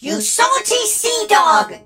darryl_hurt_vo_04.ogg